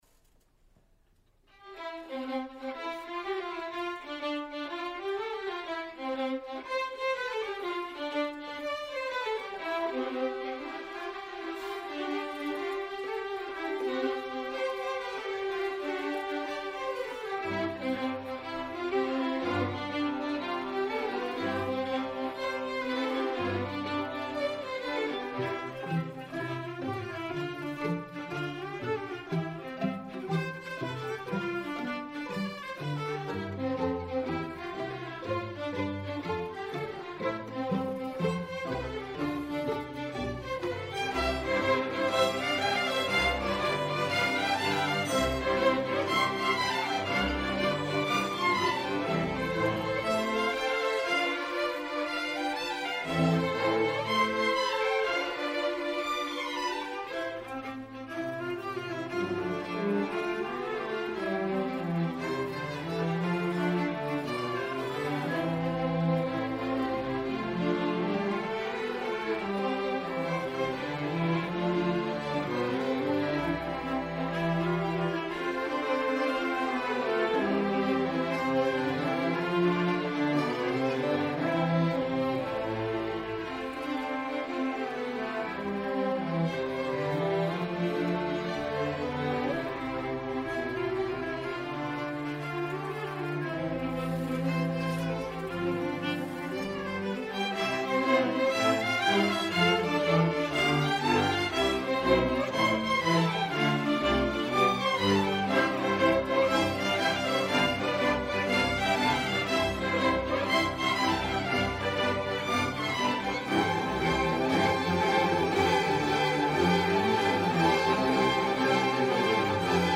Queen City Community Orchestra
Fall 2022 Concert